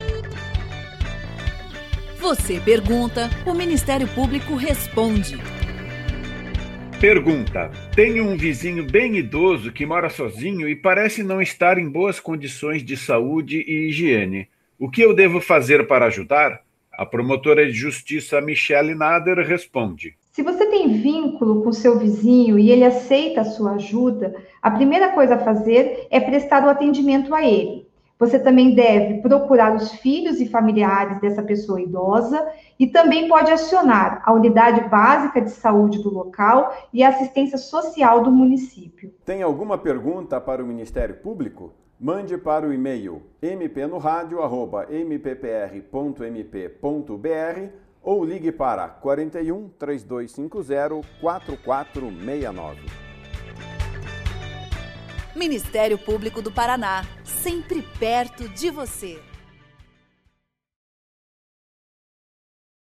Em áudios curtos, de até um minuto, procuradores e promotores de Justiça esclarecem dúvidas da população sobre questões relacionadas às áreas de atuação do Ministério Público.
Os spots do MP Responde são produzidos pela Assessoria de Comunicação do Ministério Público do Paraná.